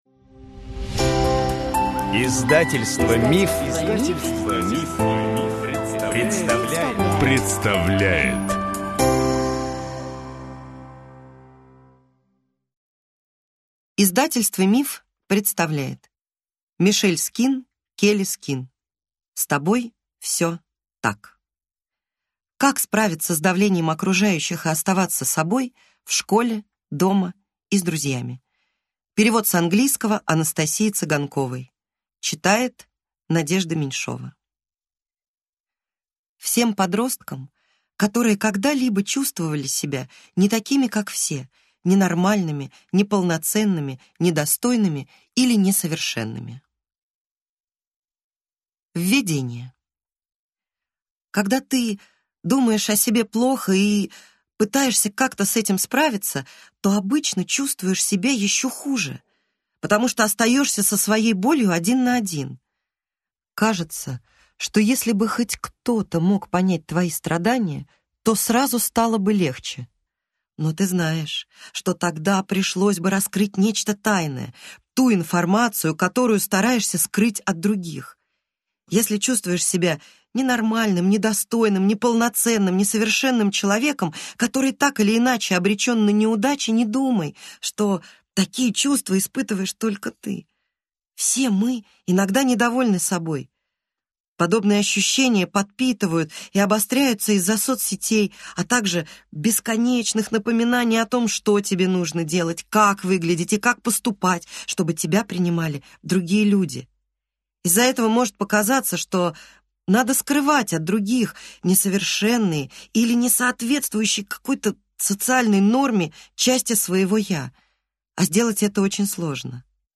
Аудиокнига С тобой всё так! Как справиться с давлением окружающих и оставаться собой – в школе, дома и с друзьями | Библиотека аудиокниг